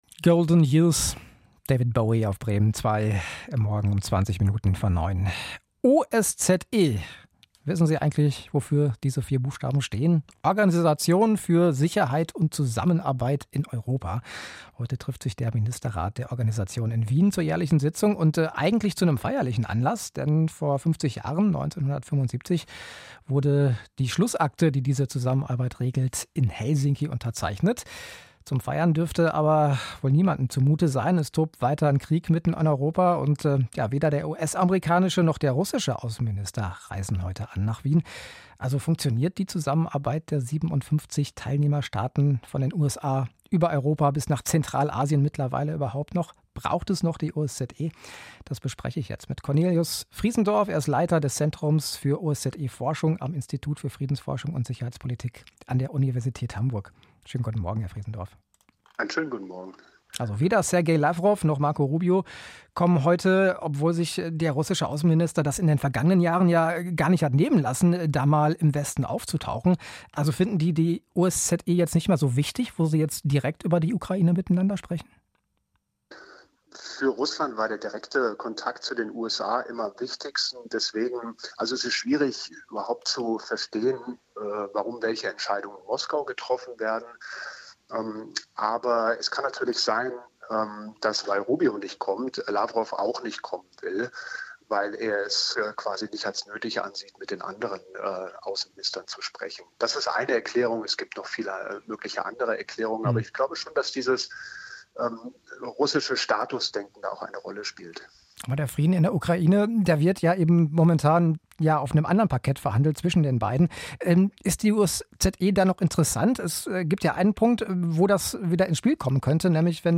Interview mit Bremen Zwei